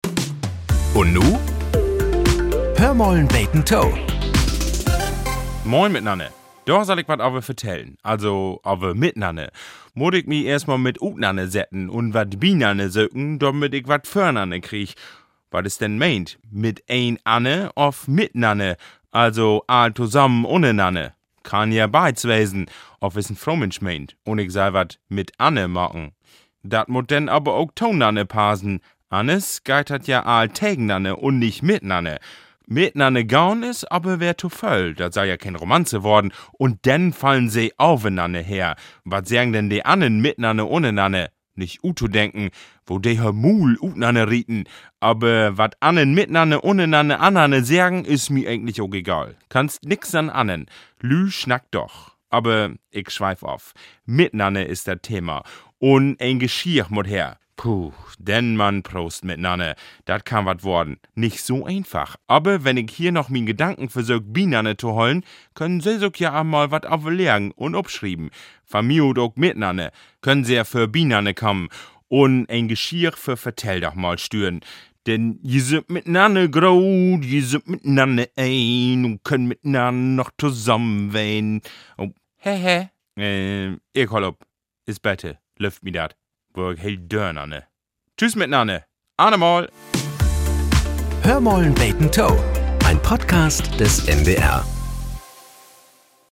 Nachrichten - 24.02.2025